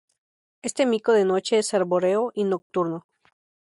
Pronounced as (IPA) /ˈmiko/